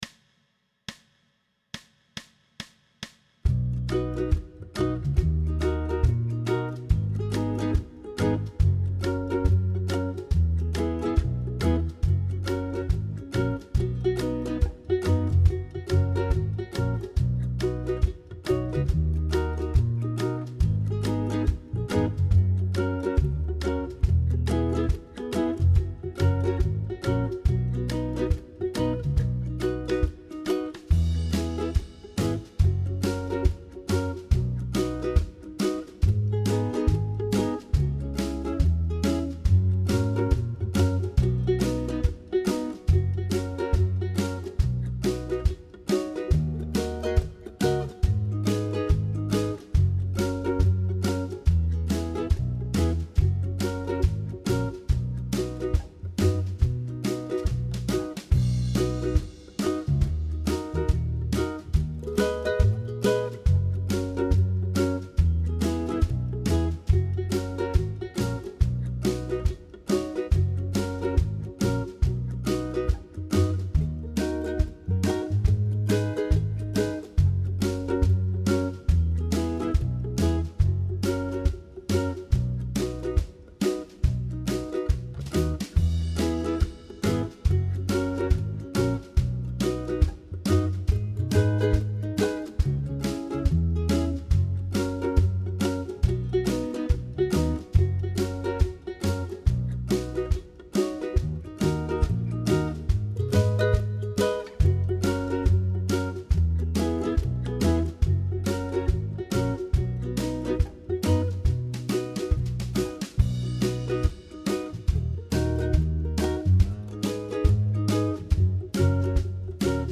Pop style jam track with Ukulele Rhythm
Tempo: 140BPM
Key of B
ukulele-pop-140-b.mp3